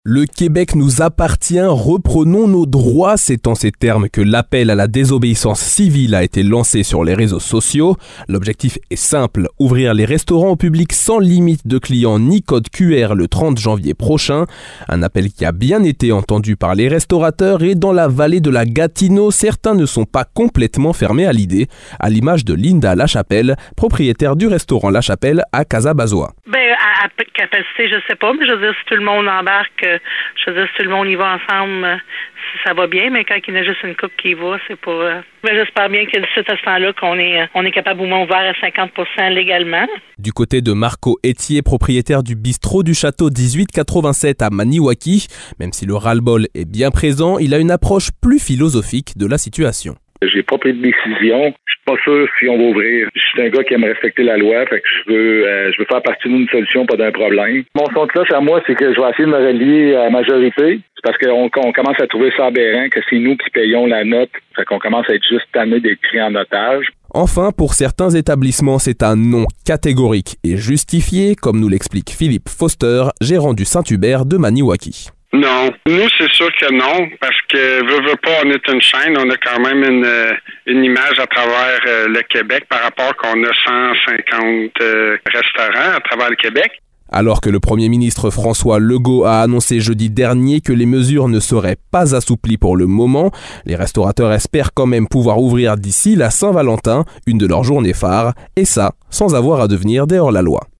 Épisode [Reportage] Nos restaurateurs - 24 janvier 2022